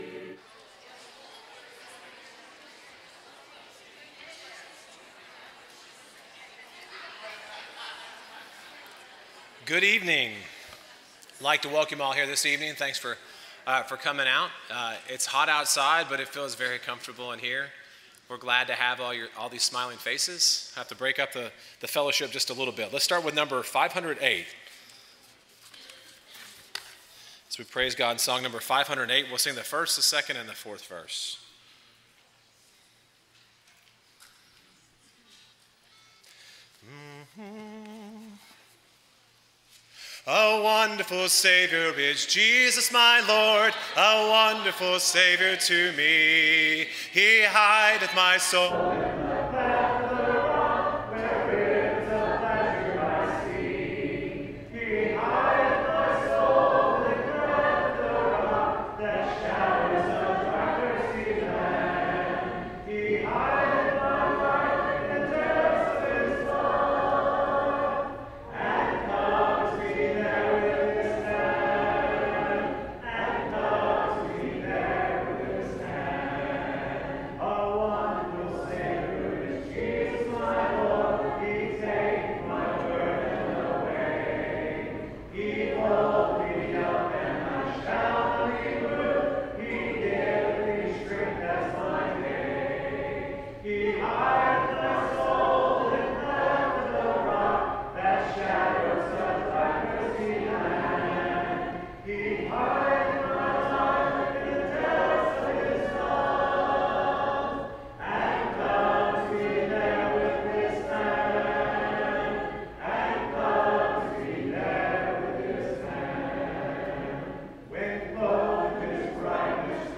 Luke 12:32, English Standard Version Series: Sunday PM Service